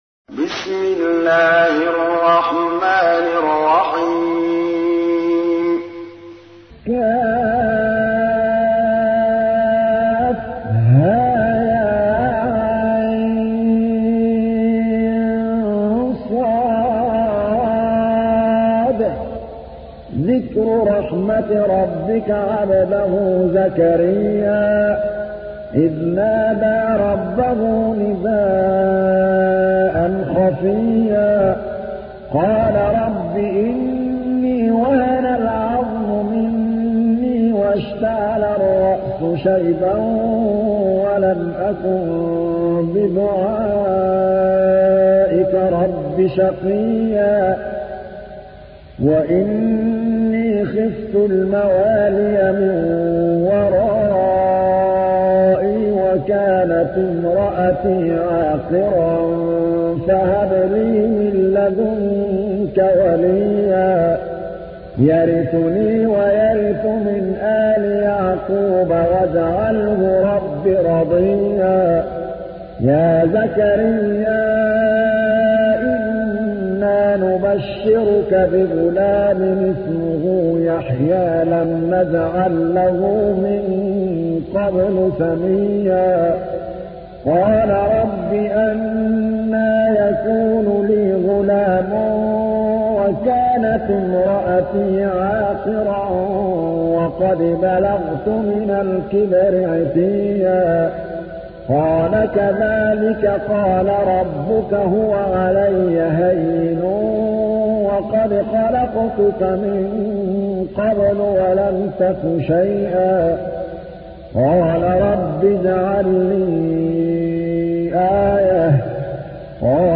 تحميل : 19. سورة مريم / القارئ محمود الطبلاوي / القرآن الكريم / موقع يا حسين